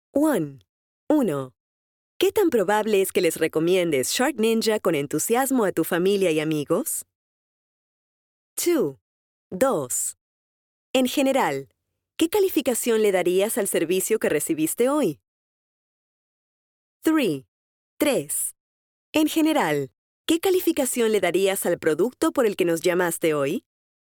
Female
Approachable, Character, Conversational, Corporate, Natural, Warm, Young
Educational_kids.mp3
Microphone: Manley reference Cardioid